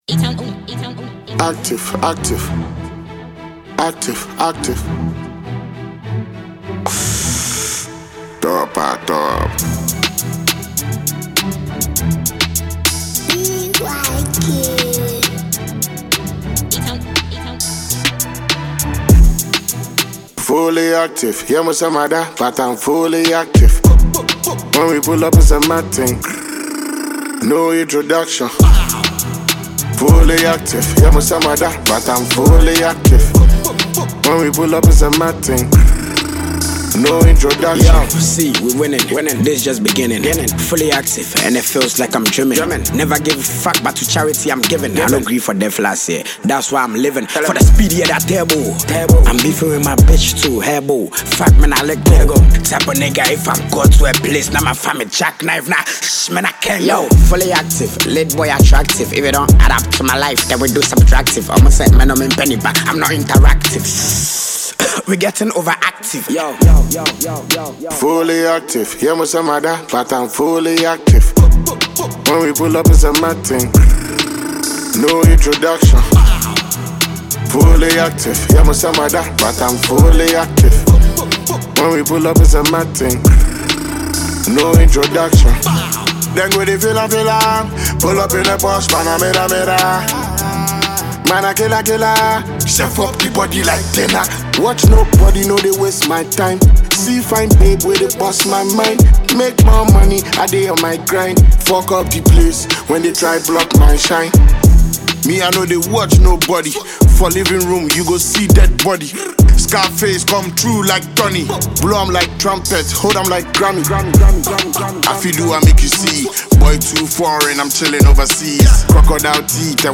Enjoy a new tune from Ghanaian rapper